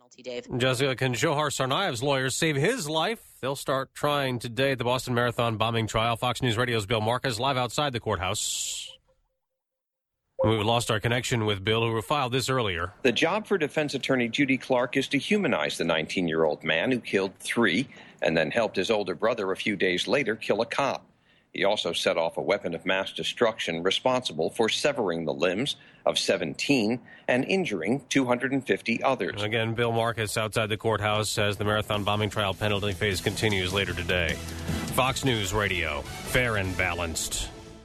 (BOSTON) APRIL 27 – FOX NEWS RADIO – FOX NEWS RADIO, 7AM (Live report encountered technical difficulty.)